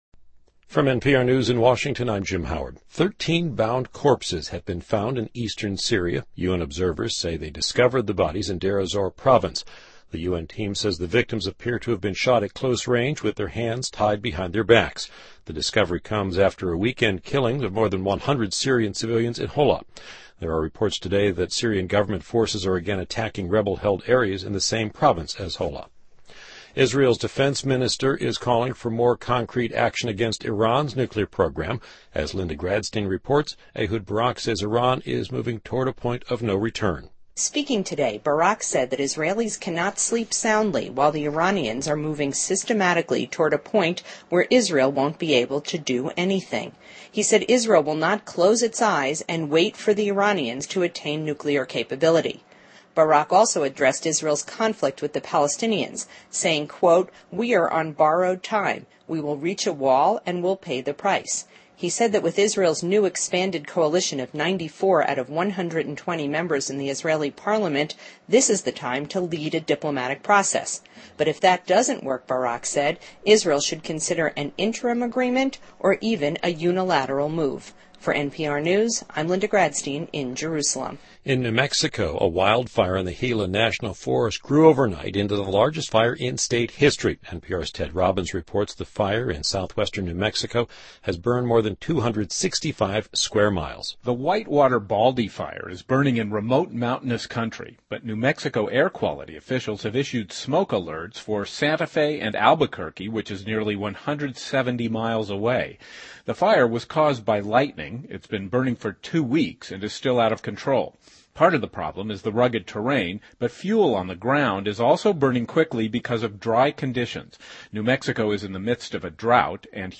NPR News,2012-05-31